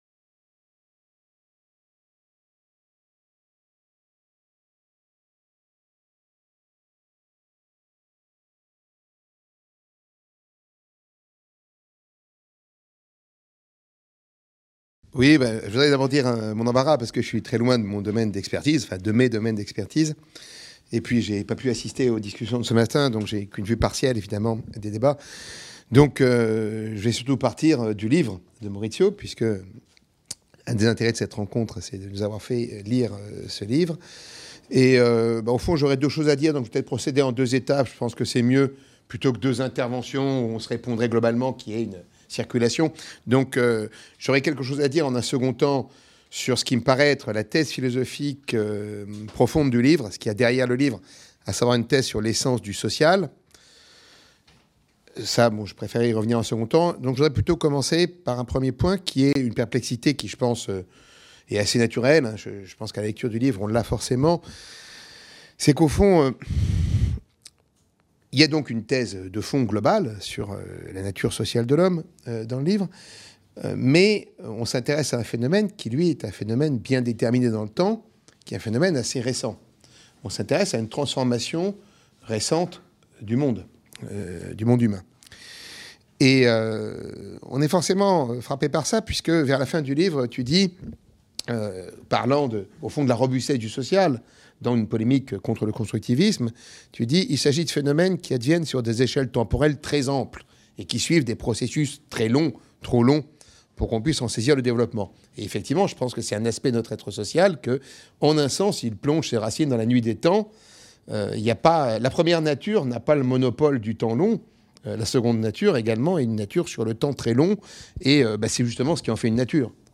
Dans la toile du Web - 7 - Conclusion et perspectives : Dialogue entre Maurizio Ferraris et Jocelyn Benoist (Université Paris 1 Panthéon-Sorbonne) | Canal U